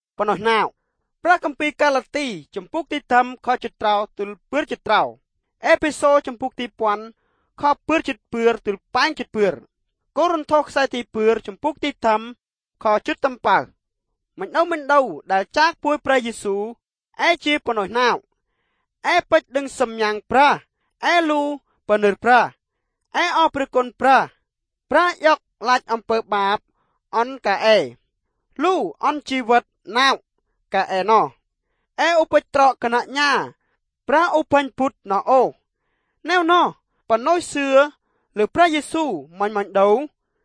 The intonation reminds me of Thai in places, whilst some of the phonetics reind me of Indian languages.